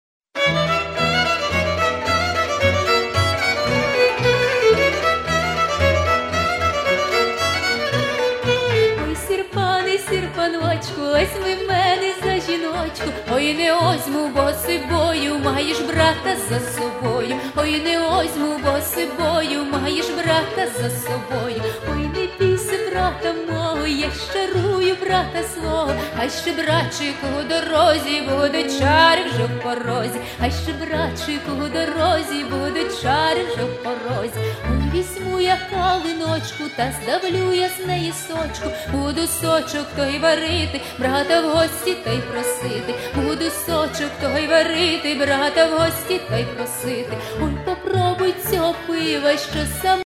Folk (248)